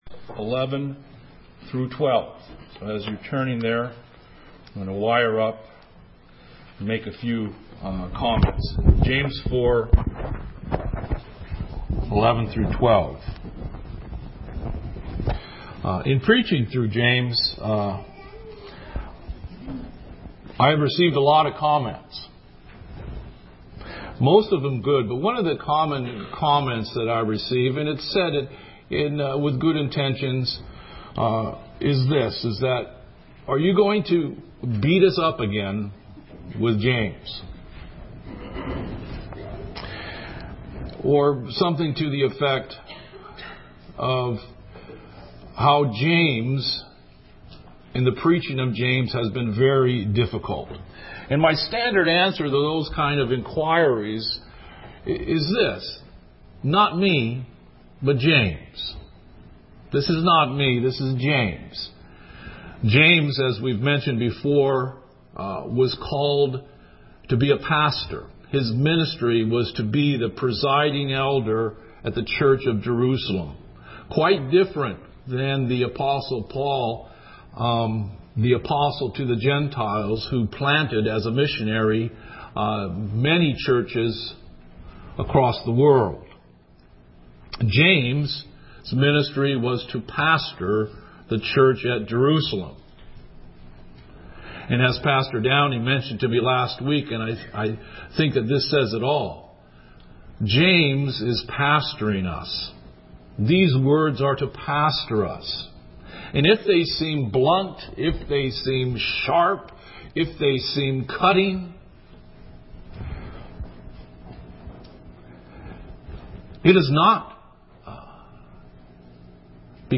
Sermons - Sovereign Grace Baptist Church of Silicon Valley